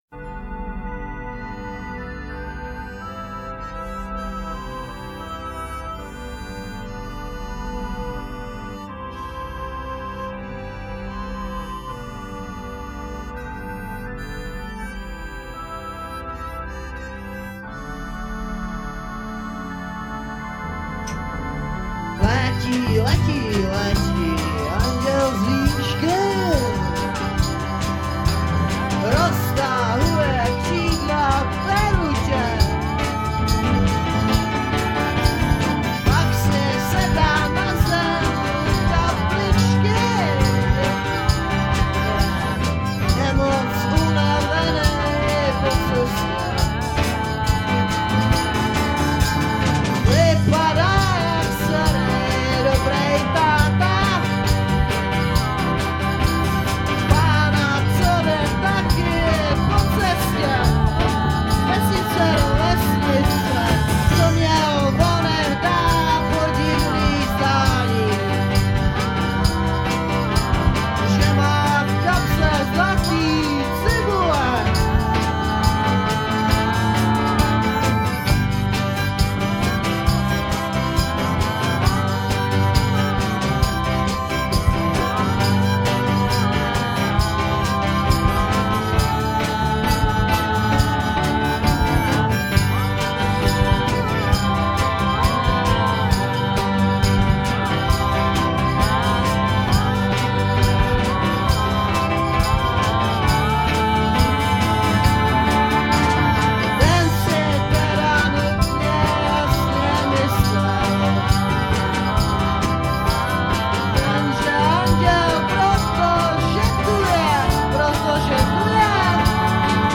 Recorded & mixed: Studánka, Delta, 1987
g, pno, voc
voc, fl